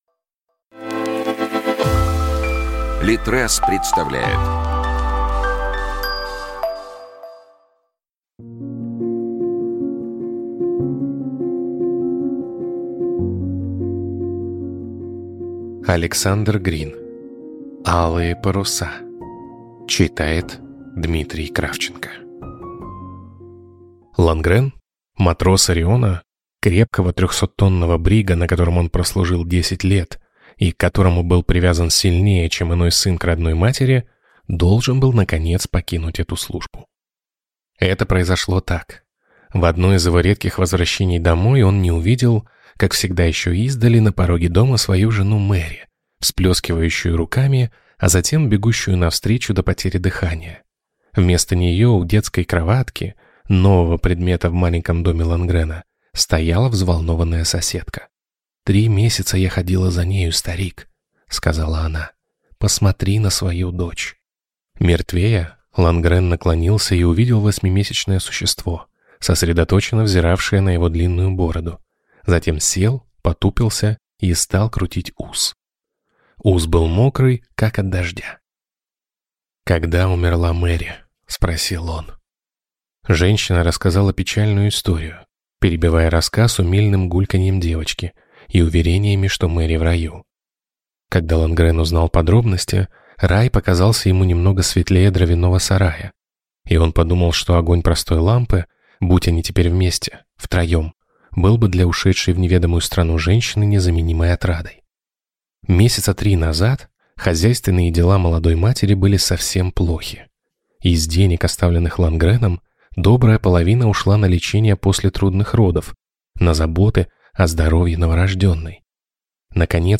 Аудиокнига Алые паруса | Библиотека аудиокниг